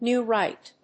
アクセントNéw Ríght